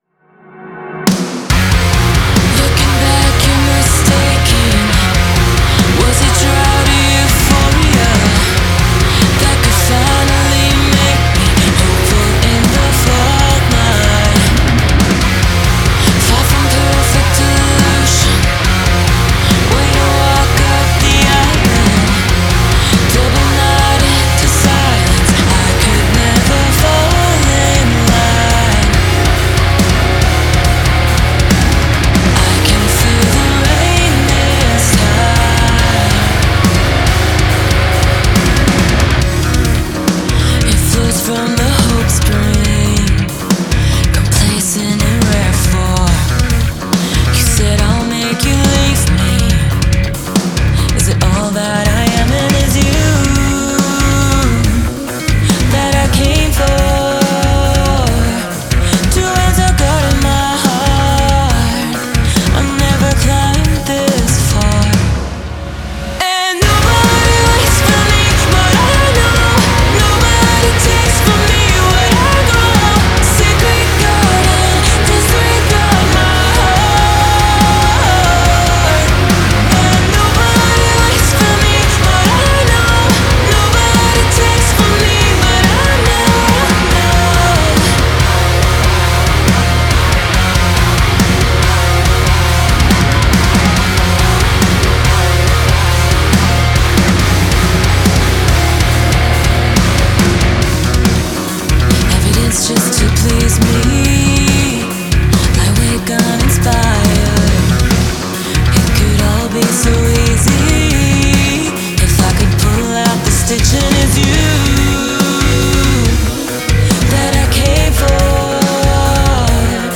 Genres : Alternative Metal, Djent